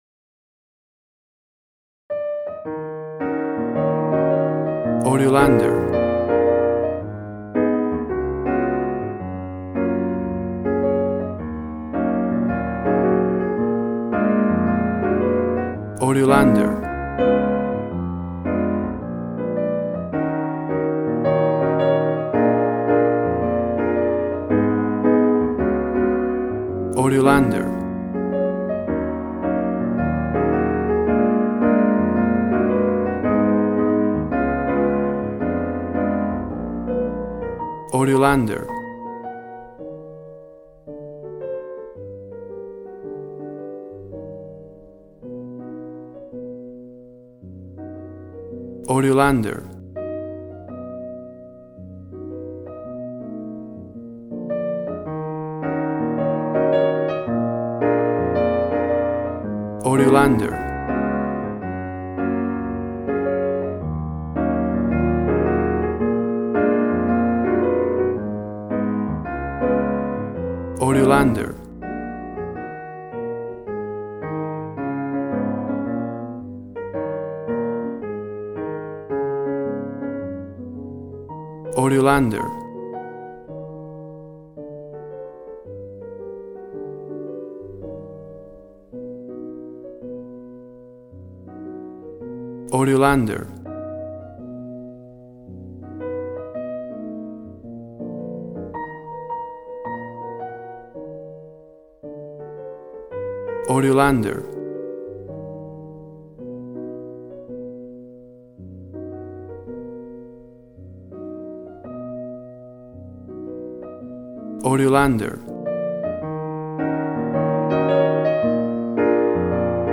Smooth jazz piano mixed with jazz bass and cool jazz drums.
Tempo (BPM): 110